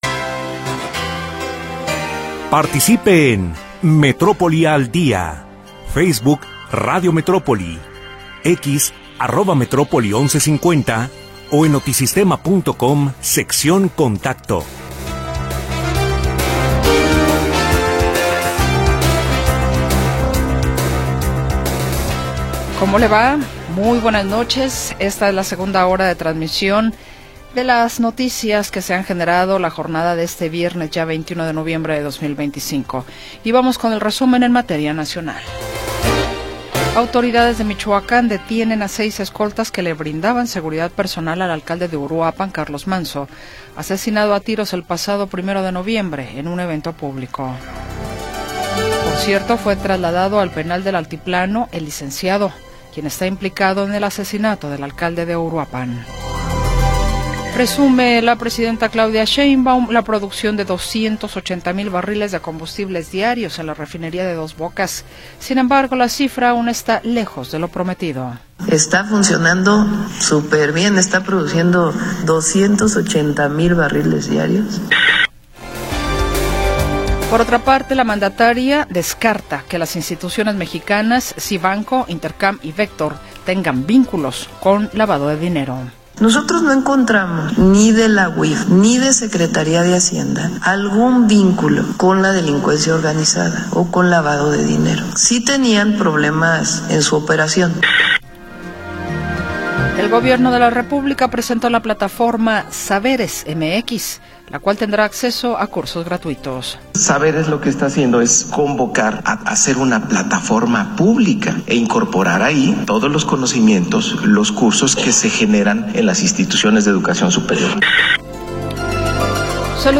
La historia de las últimas horas y la información del momento.